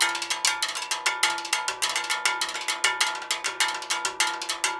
Index of /90_sSampleCDs/USB Soundscan vol.36 - Percussion Loops [AKAI] 1CD/Partition B/03-100STEELW